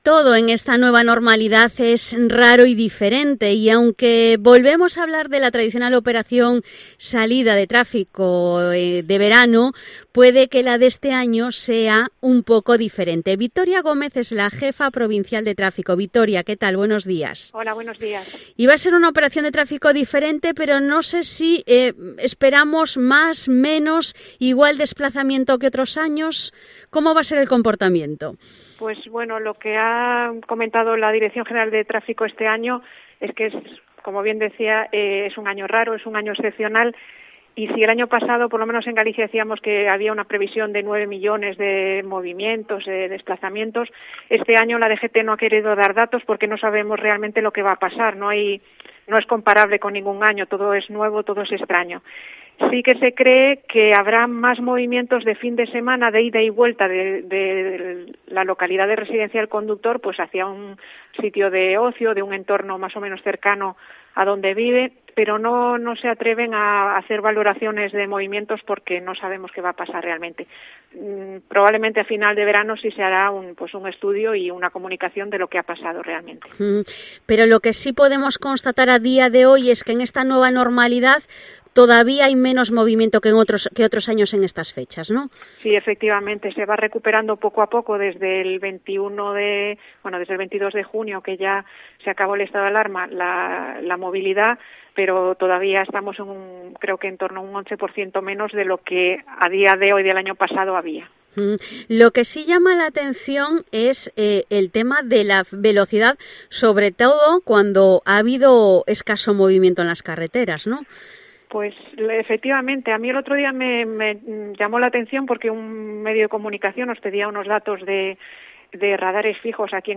Entrevista Victoria Gómez, jefa provincial de tráfico